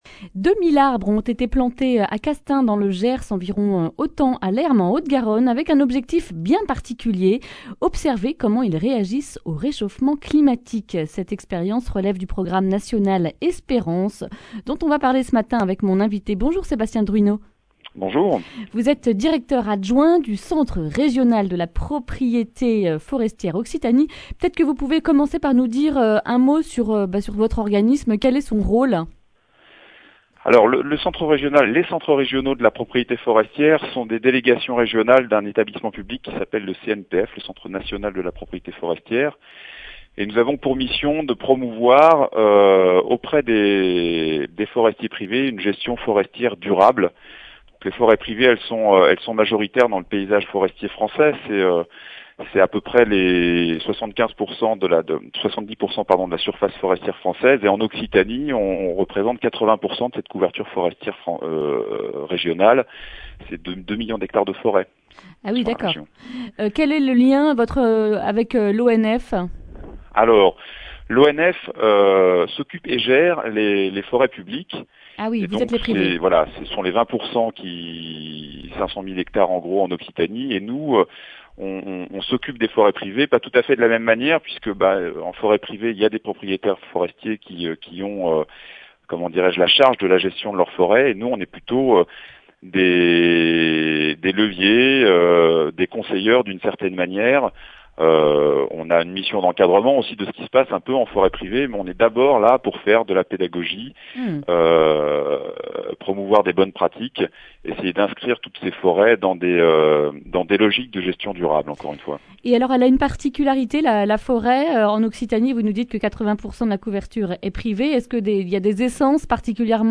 mercredi 12 mai 2021 Le grand entretien Durée 10 min
Journaliste